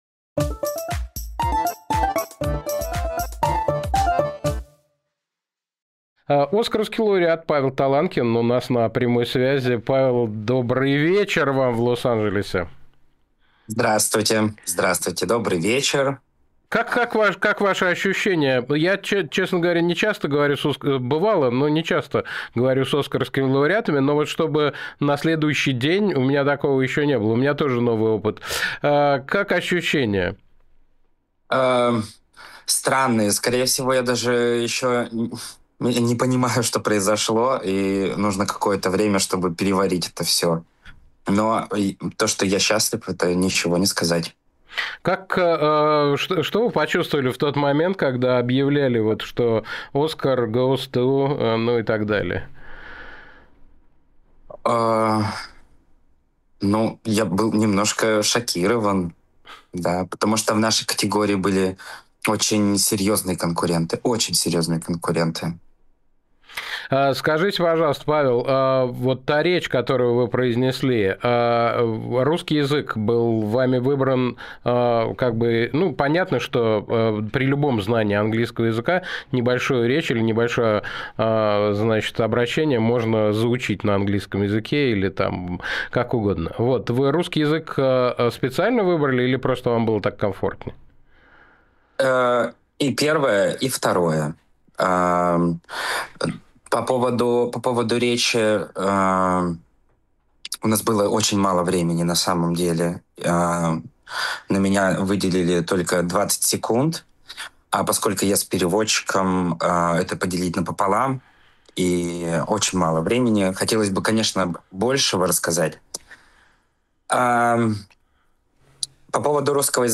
Александр Плющев журналист Павел Таланкин лауреат премии «Оскар»